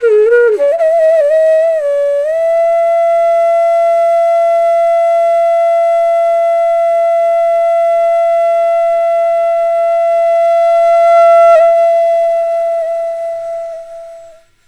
FLUTE-A09 -R.wav